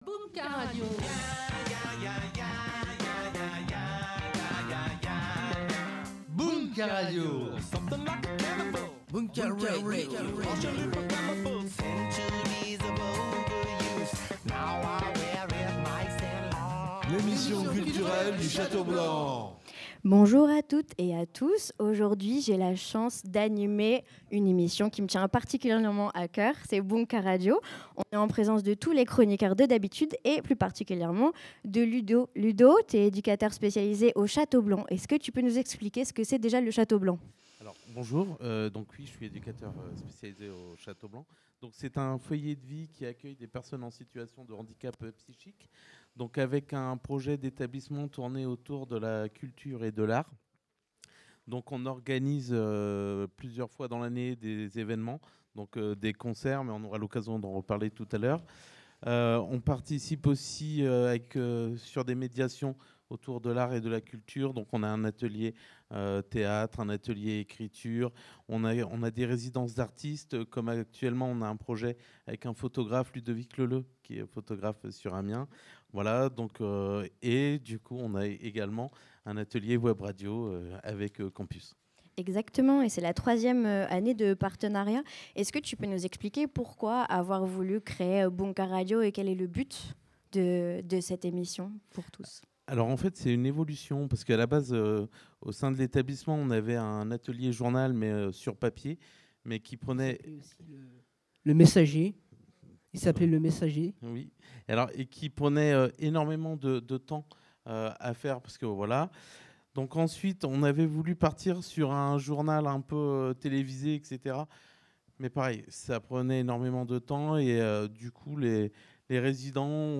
Samedi 22 mars, les animateurs et animatrices Radio Campus sur scène au Chaudron - Scène étudiante du Crous et en direct !
Bunka Radio sur scène et en direct